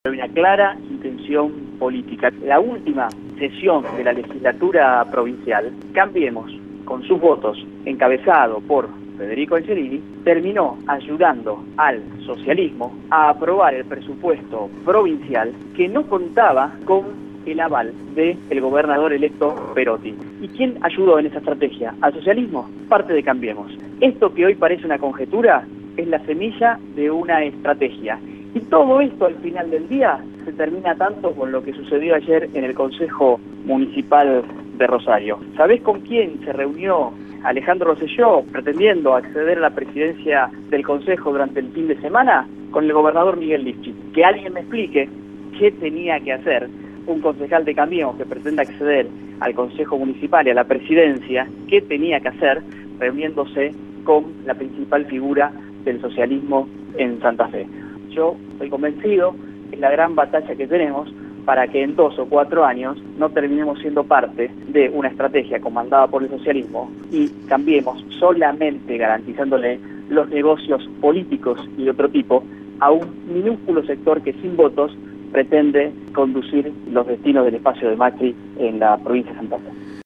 ROY-LOPEZ-MOLINA-Concejal-Cambiemos.mp3